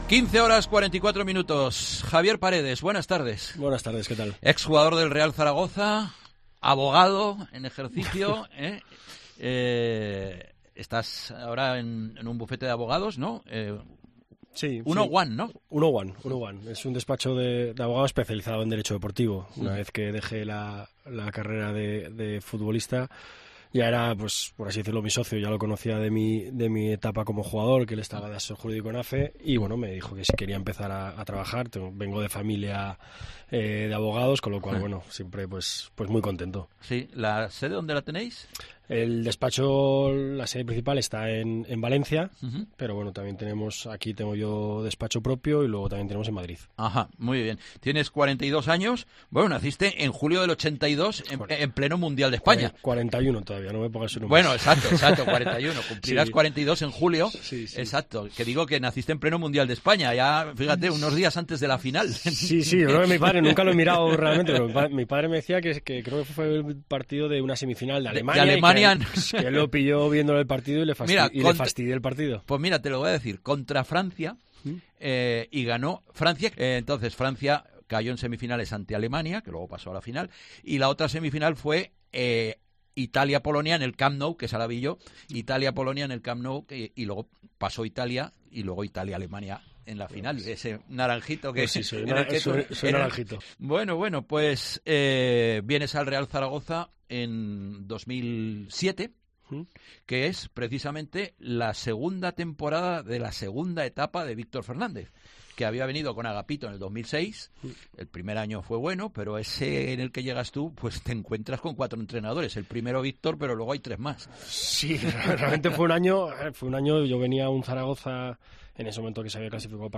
en los estudios de COPE Zaragoza